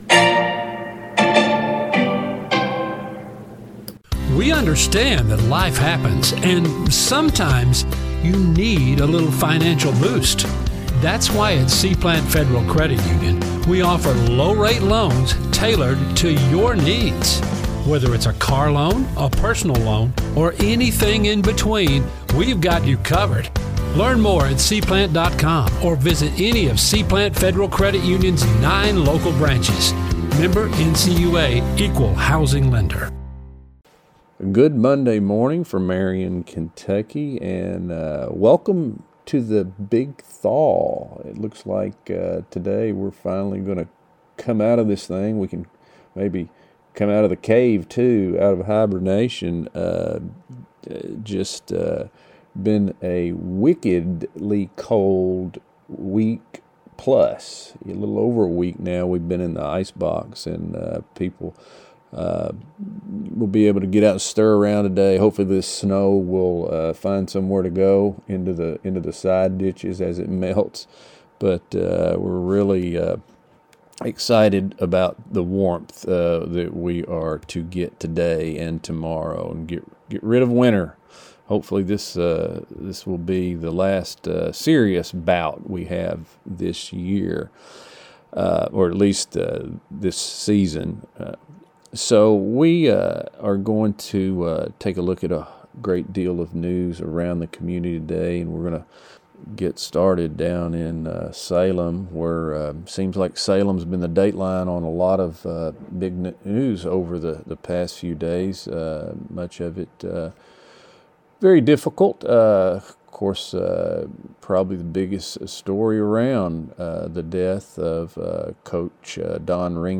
C-PLANT | Monday NEWScast LISTEN NOW News | Sports | Weather Today we talk to Mayor D'Anna Browning By Crittenden Press Online at February 02, 2026 Email This BlogThis!